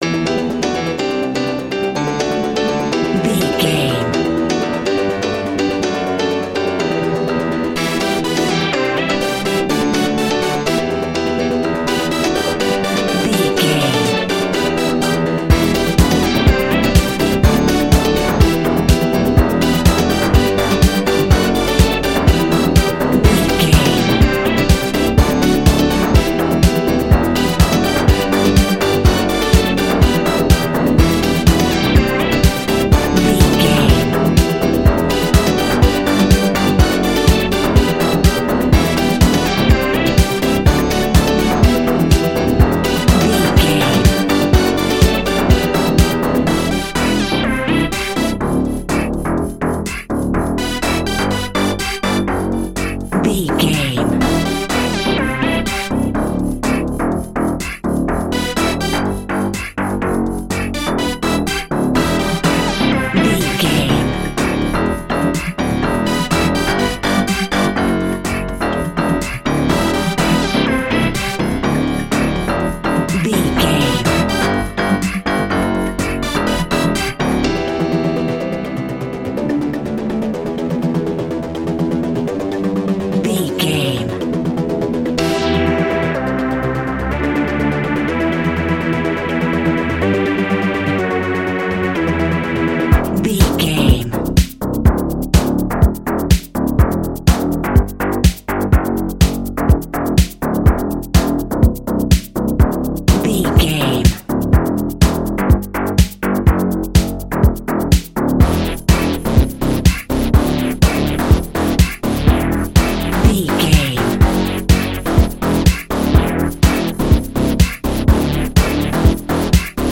Aeolian/Minor
energetic
hypnotic
drum machine
piano
synthesiser
electronic
techno
trance
instrumentals
synth bass